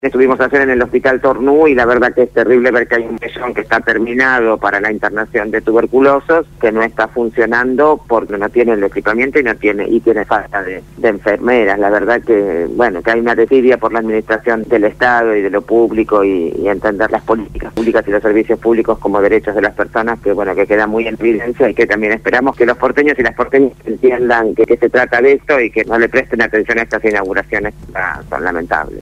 Gabriela Alegre, Legisladora de la Ciudad por el Encuentro Popular para la Victoria, habló con Radio Gráfica FM 89.3